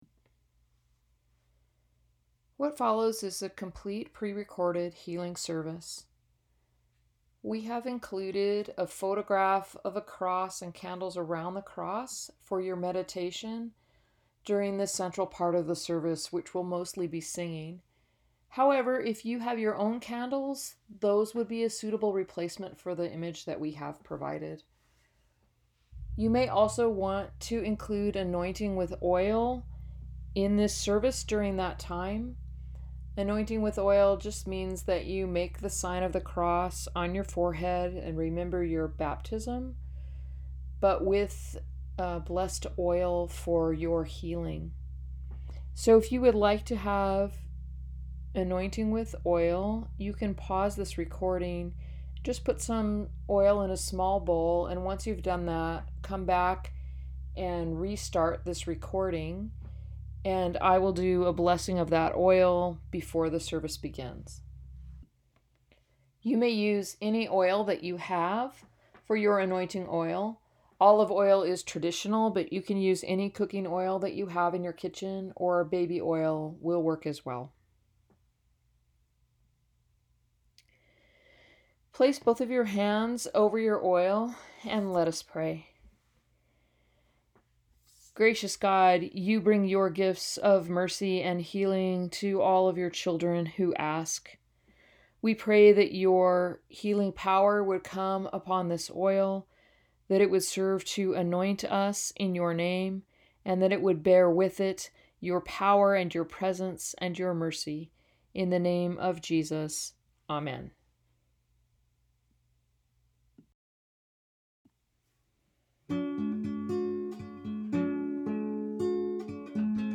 Sermons | Church of the Cross